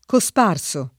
DOP: Dizionario di Ortografia e Pronunzia della lingua italiana
vai all'elenco alfabetico delle voci ingrandisci il carattere 100% rimpicciolisci il carattere stampa invia tramite posta elettronica codividi su Facebook cosparso [ ko S p # r S o ] part. pass. di cospargere e agg.